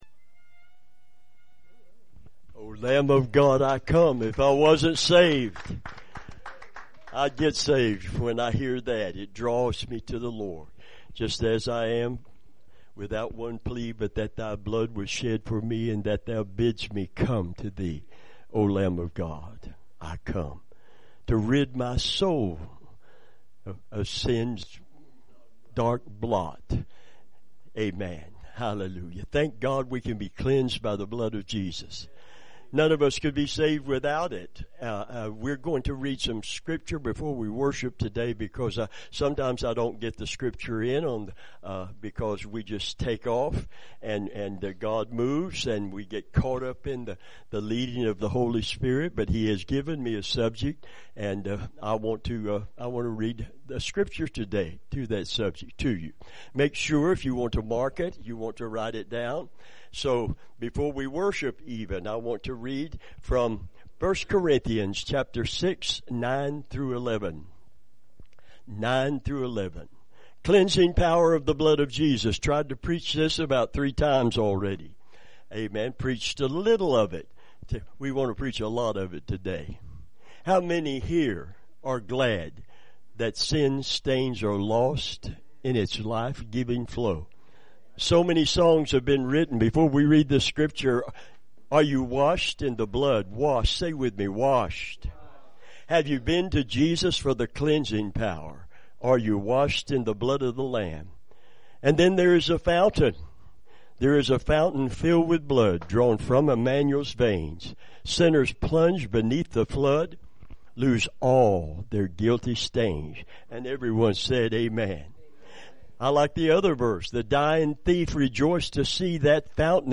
Revival Sermons